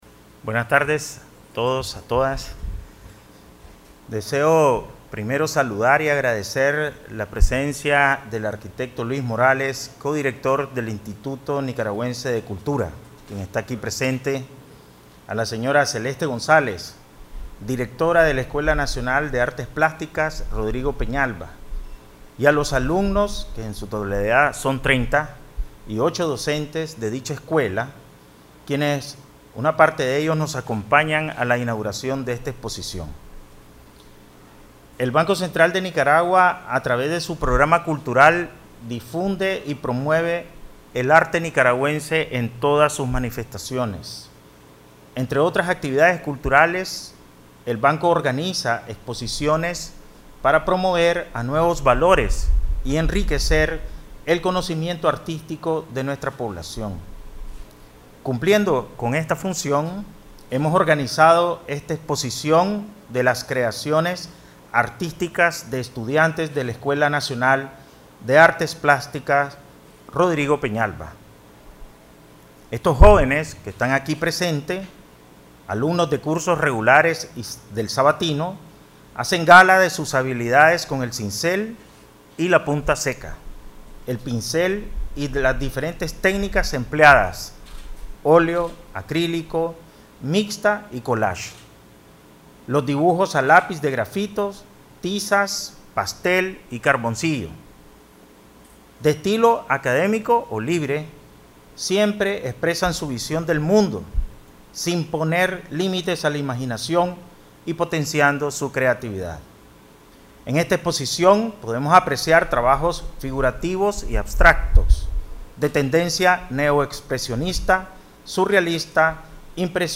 BCN inaugura exposición de Escuela Nacional de Artes Plásticas
Palabras del Presidente del BCN, Ovidio Reyes R.